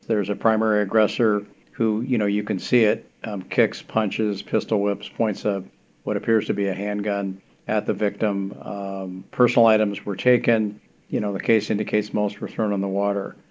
Police Chief Brian Smith describes what was seen in the video, which has since been taken down by the social media platforms.